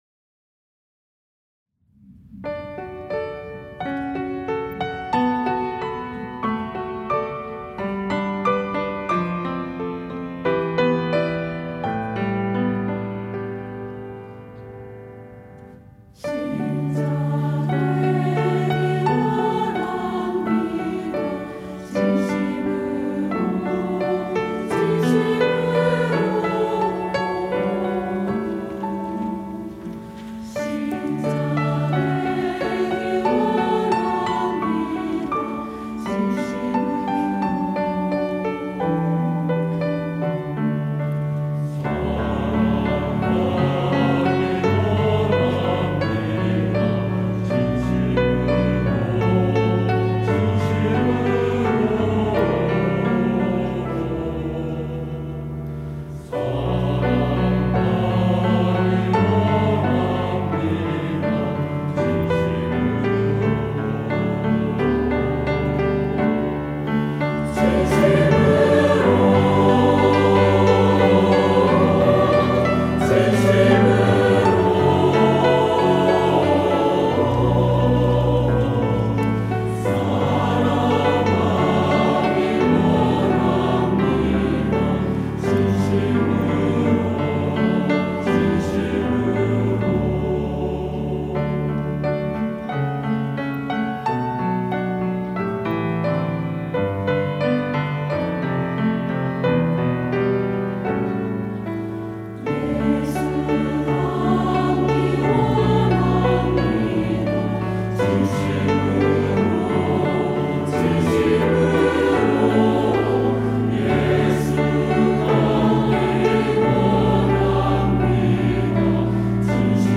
할렐루야(주일2부) - 신자되기 원합니다
찬양대